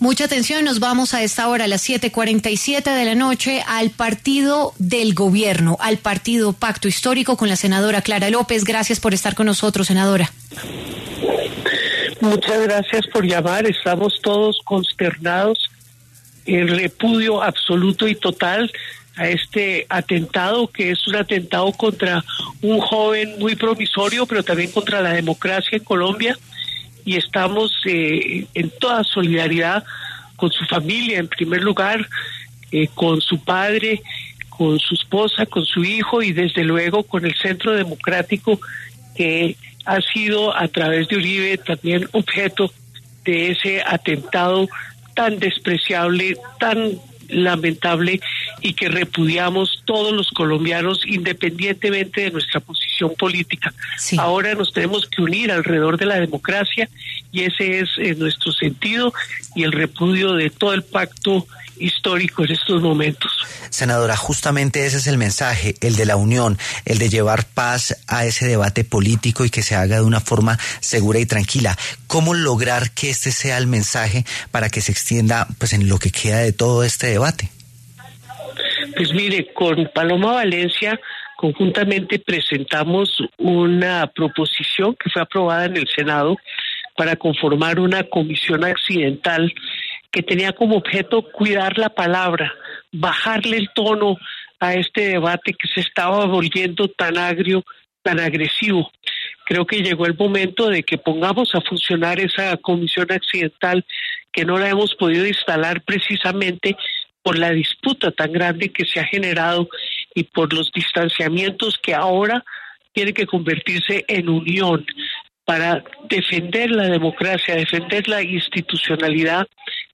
Al respecto, W Radio conversó con varias voces, una de esas fue la senadora Clara López del partido del gobierno, Pacto Histórico.